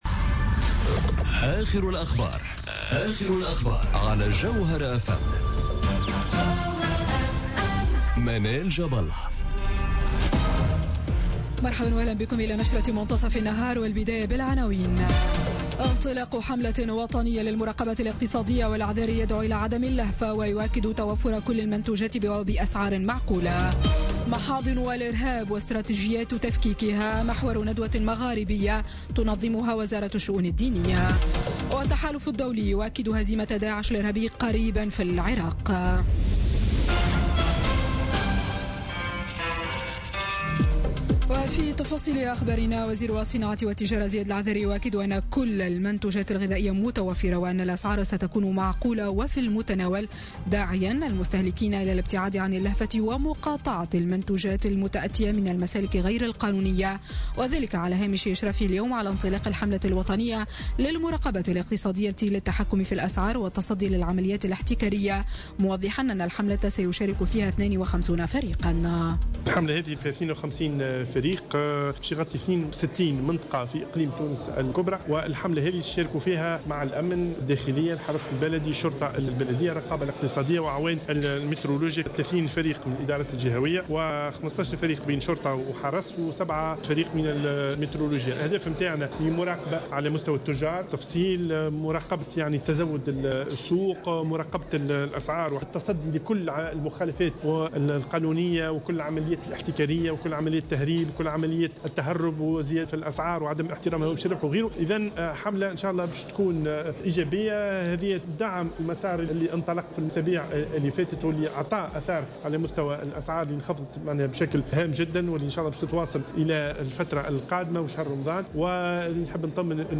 نشرة أخبار منتصف النهار ليوم الثلاثاء 16 ماي 2017